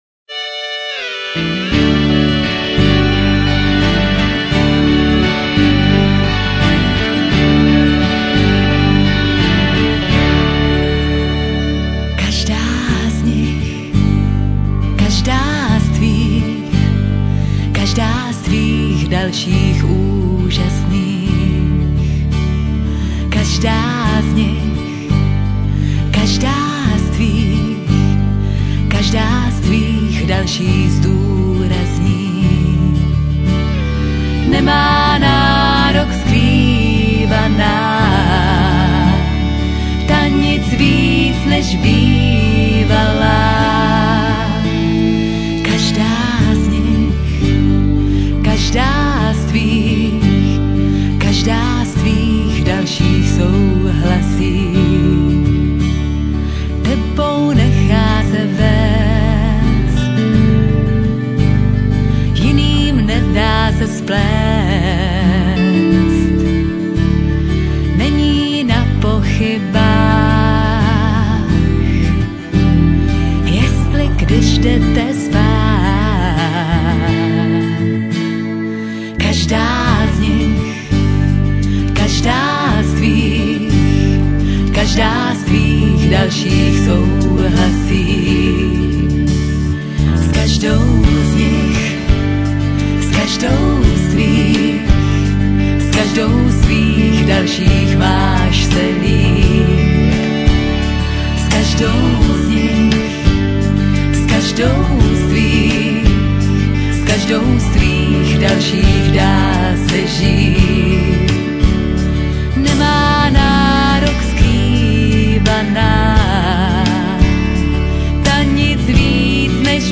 mastering:   Mercury studio Rokycany 2005-06,